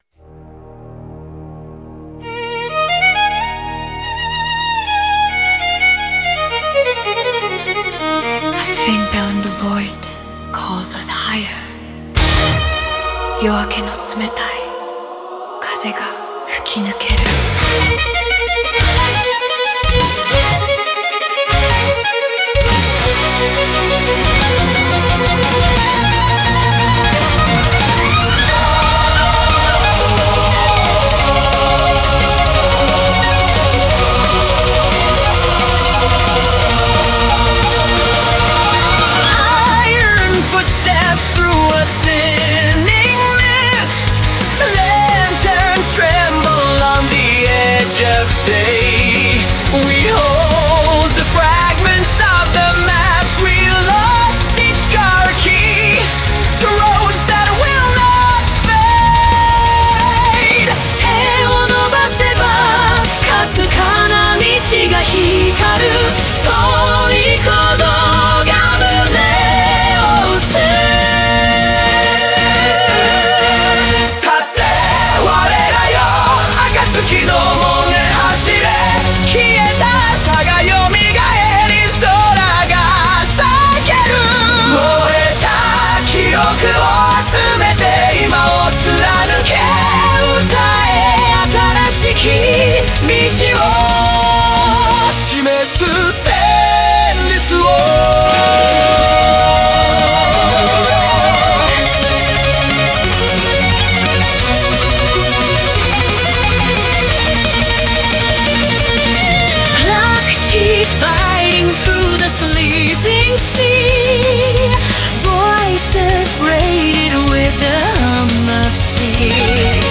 Melodic Power Metal
Use plates on fast sections and long halls on climaxes.